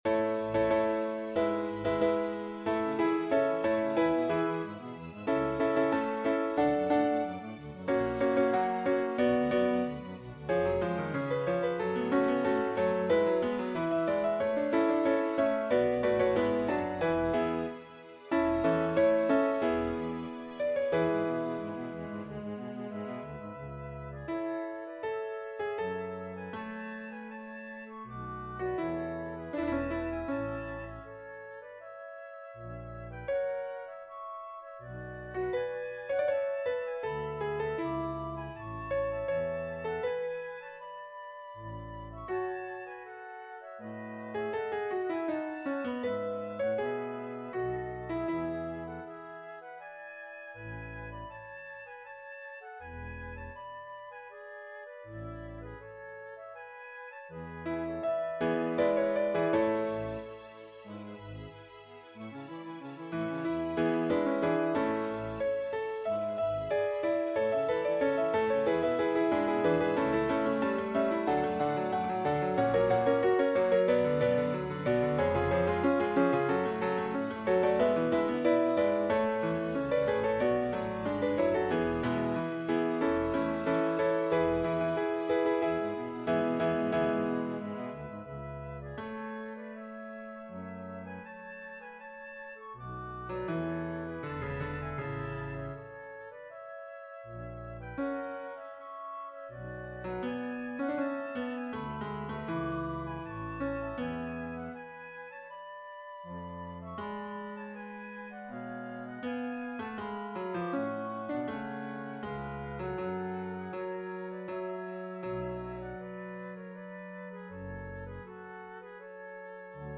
MP3 Dateien von allen Chorstücken nach Register
BWV234-2 Gloria_SATB.mp3